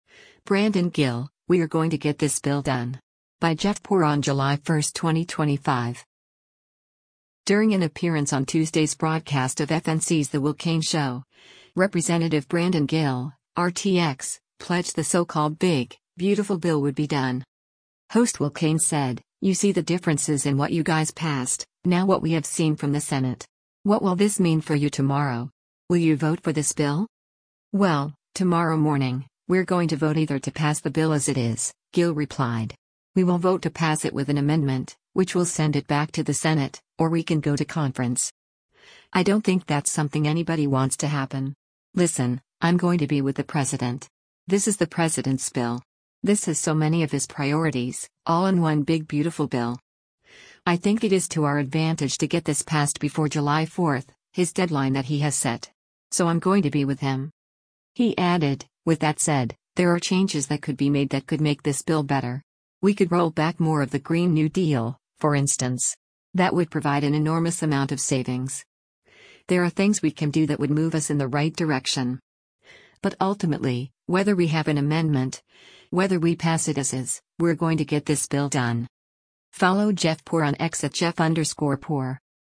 During an appearance on Tuesday’s broadcast of FNC’s “The Will Cain Show,” Rep. Brandon Gill (R-TX) pledged the so-called “Big, Beautiful Bill” would be “done.”